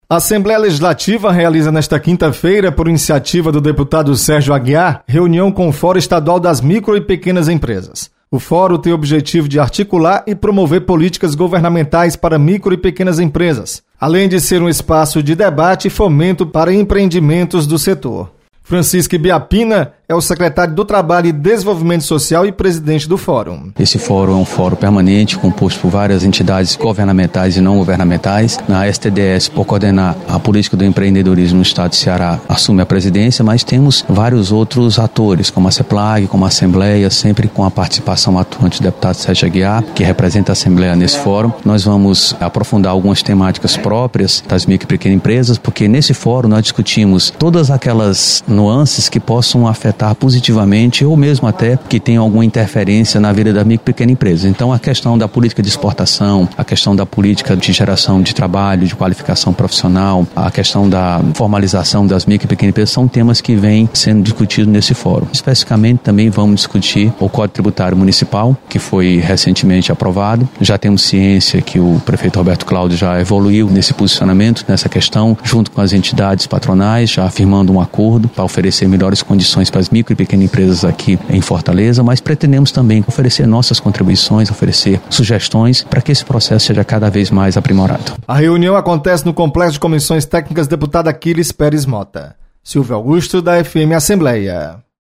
Você está aqui: Início Comunicação Rádio FM Assembleia Notícias Reunião